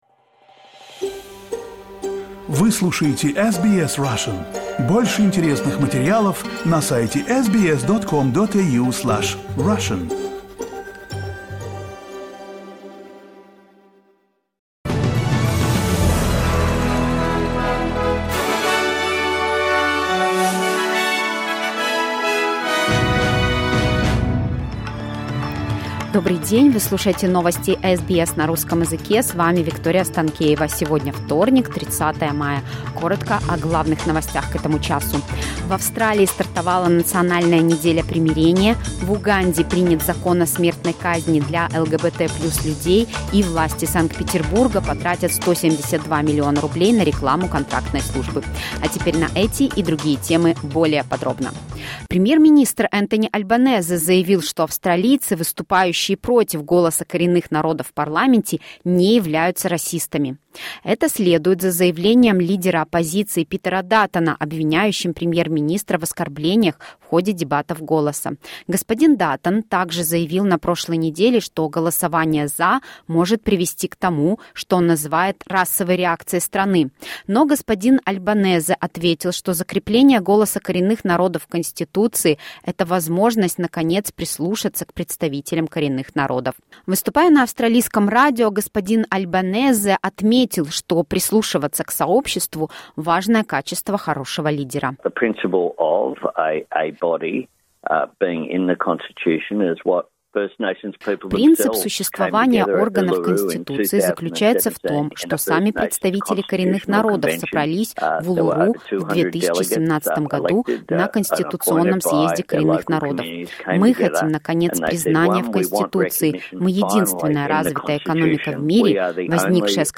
SBS news in Russian — 30.05.2023